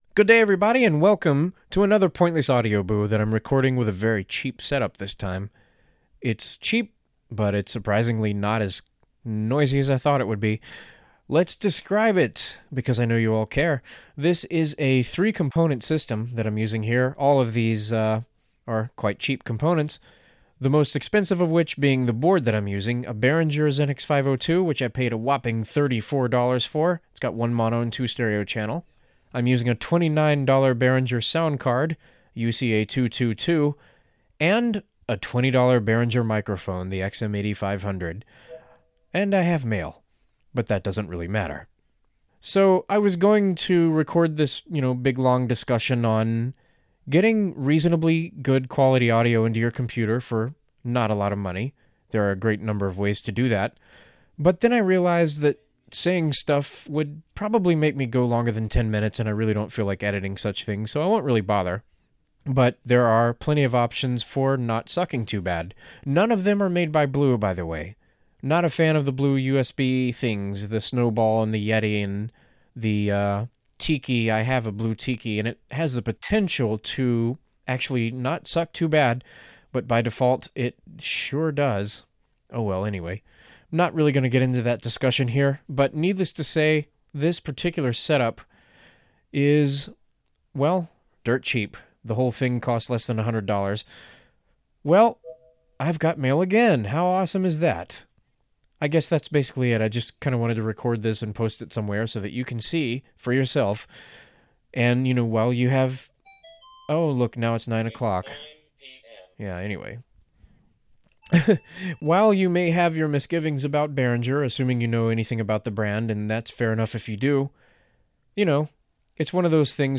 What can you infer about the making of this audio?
This short post was recorded using all low-end Behringer equipment; a Xenyx 502 mixer, a UCA-222 audio interface, and an XM8500 dynamic microphone. Just proving the point that cheap doesn't always mean crap.